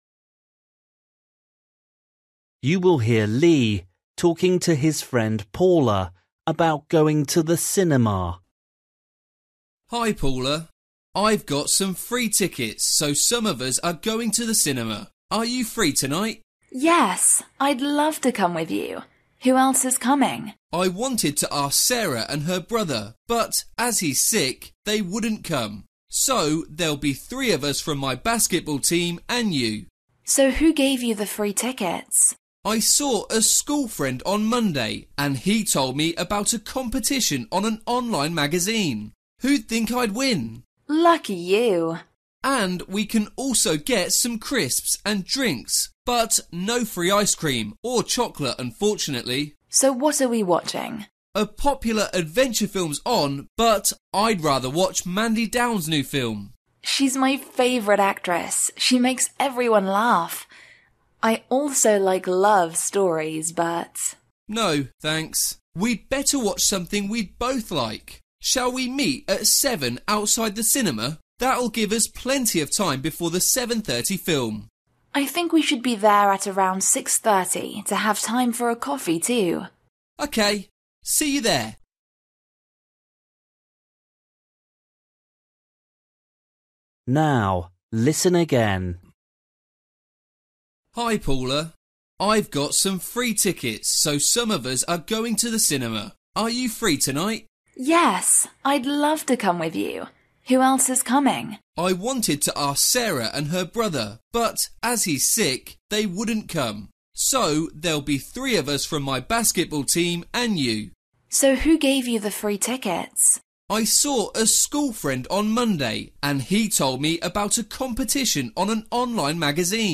Bài tập trắc nghiệm luyện nghe tiếng Anh trình độ sơ trung cấp – Nghe một cuộc trò chuyện dài phần 23